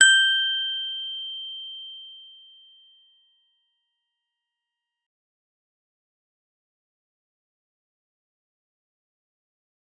G_Musicbox-G6-f.wav